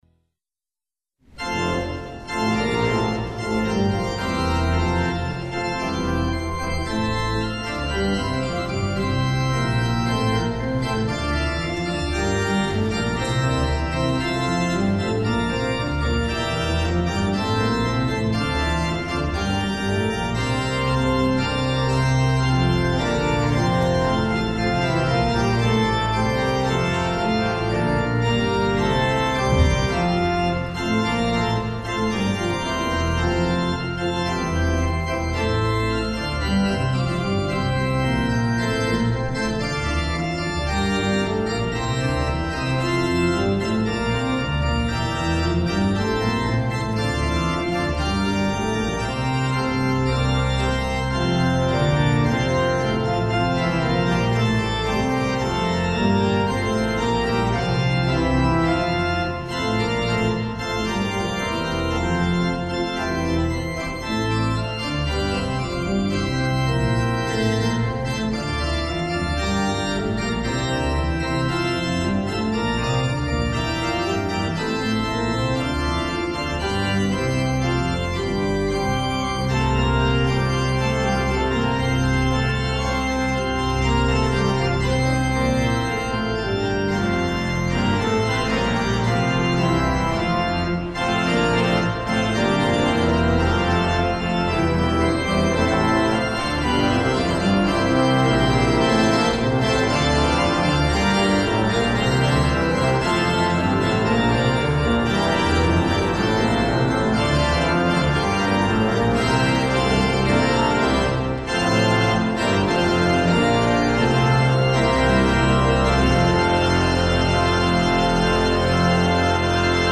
Hear the Bible Study from St. Paul's Lutheran Church in Des Peres, MO, from February 15, 2026.
Join the pastors and people of St. Paul’s Lutheran Church in Des Peres, MO, for weekly Bible study on Sunday mornings.